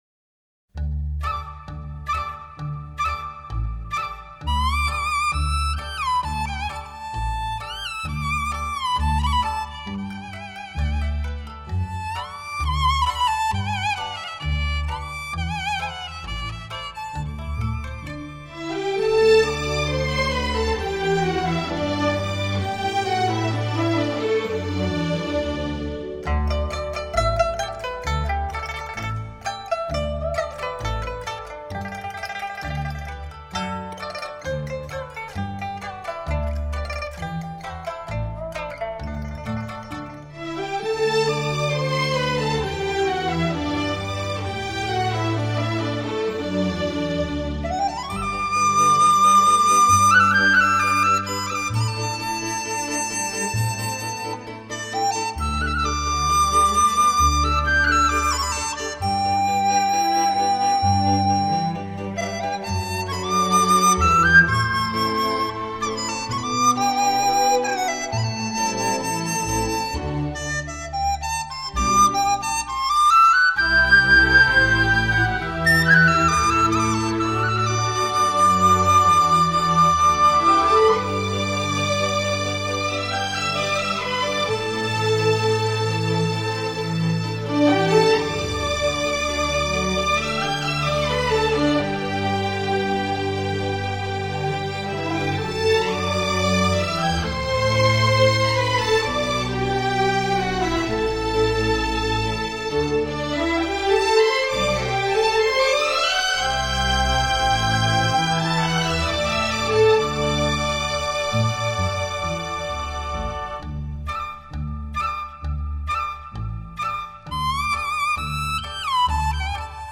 [2006-10-13]民乐合奏陕北民歌《秋收》